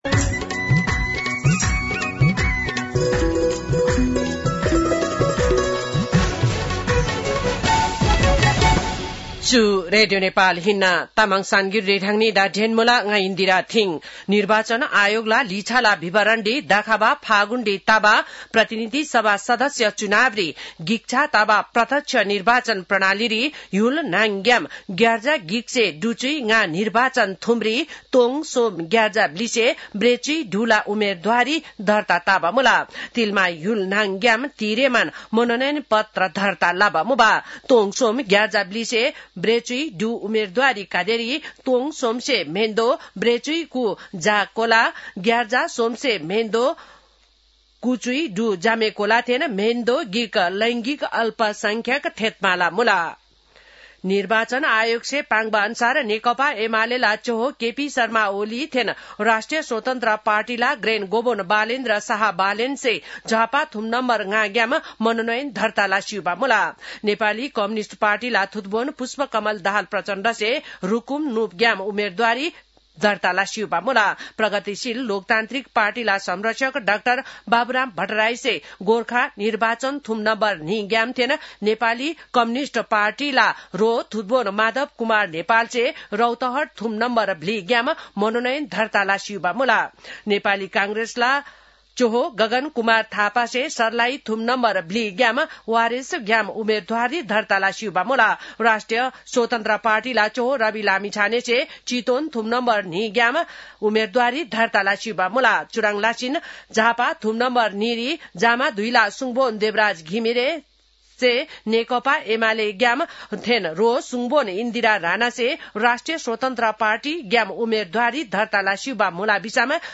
तामाङ भाषाको समाचार : ७ माघ , २०८२